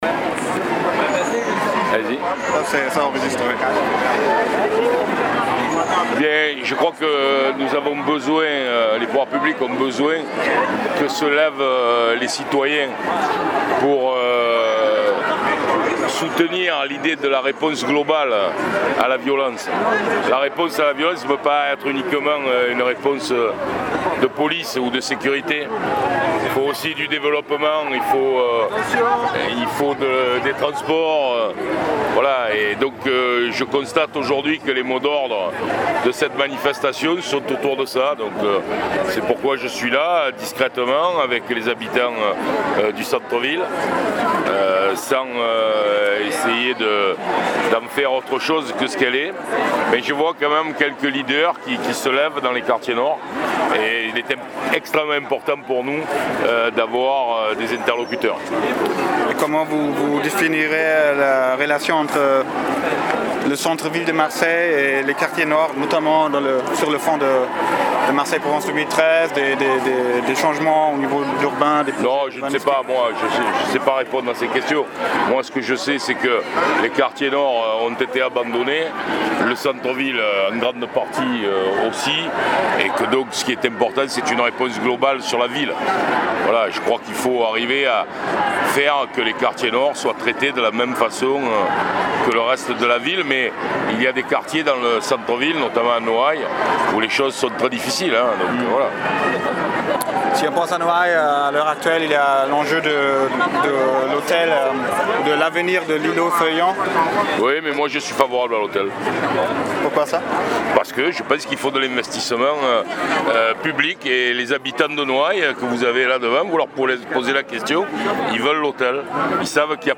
Après, vous trouvez l'entretien j'ai fait d'un manier spontané avec Menucci pendant
la manif du collectif 1 Juin.
Mais aussi la relation sonore et écrite d'un petit interview durant la manifestation du 1er juin, du Maire de secteur, M. Menucci.
Il est trés offensif et il piétine allégrement tous les lieux communs de la critique que l'on pourrait formuler, tout aussi allègrement, au sein de l'Assemblée de la Plaine.